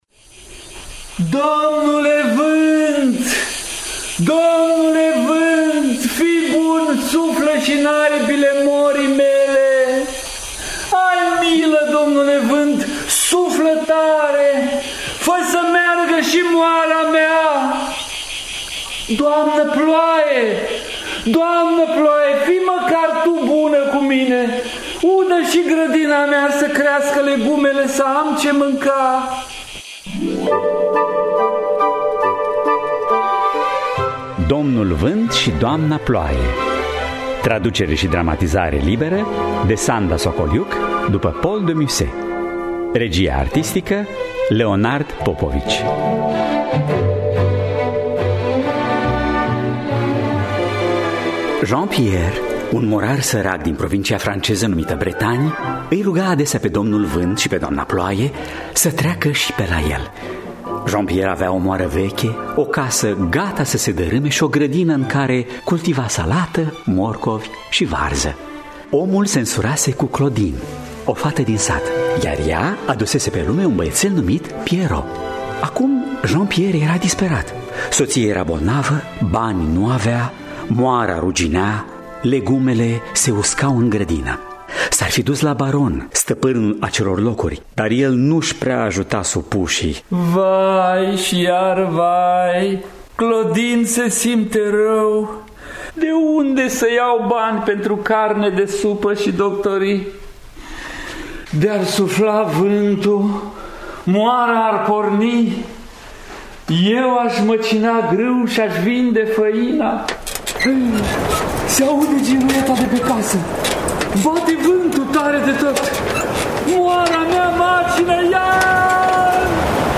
Domnul Vânt şi Doamna Ploaie după Paul de Musset – Teatru Radiofonic Online